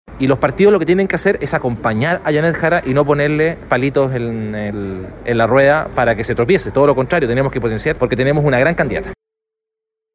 Por otra parte, este domingo, la aspirante a La Moneda lideró una sesión de fotos con candidatos al parlamento de la coalición, instancia a la que asistieron diferentes figuras del pacto, como la Diputada Karol Cariola y el exalcalde de Valparaíso, Jorge Sharp, quien es candidato a diputado por el distrito siete.